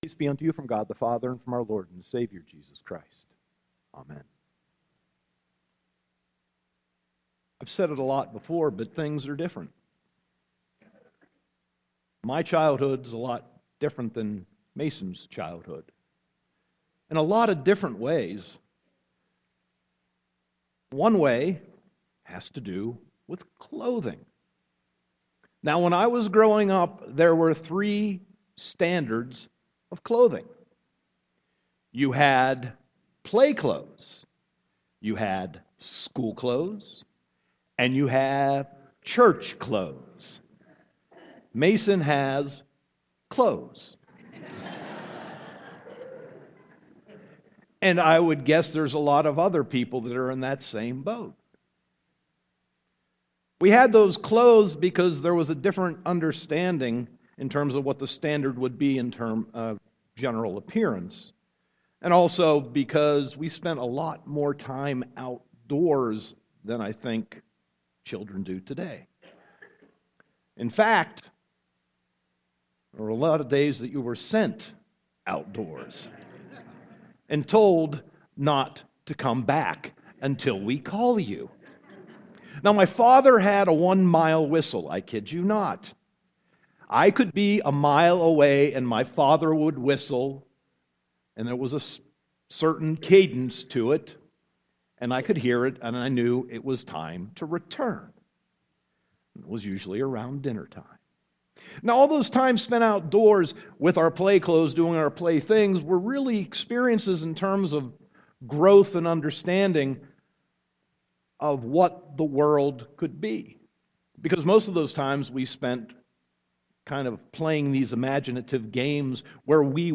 Sermon 1.17.2016